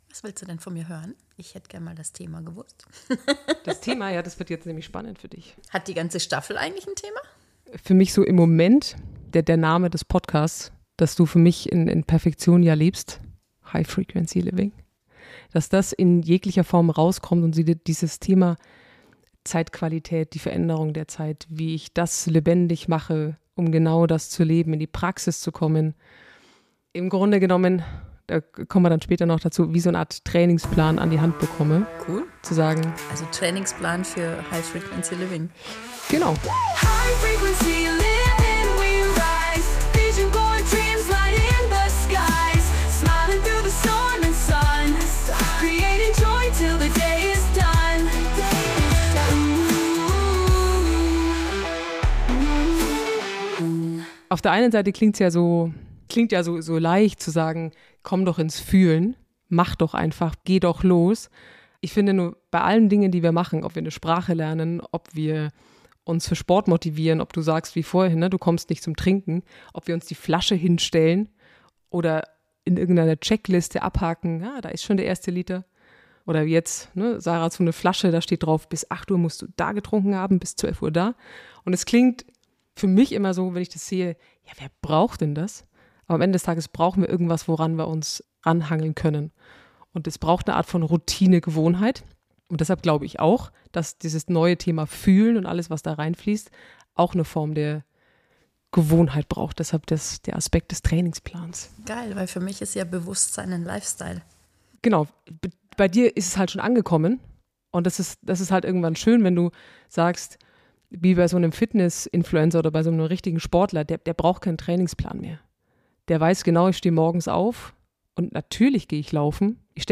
Ein Gespräch über neue Routinen, Ehrlichkeit und bewusstes Leben als gelebten Lifestyle. Warum 21 Tage alles verändern – wenn du es wirklich willst.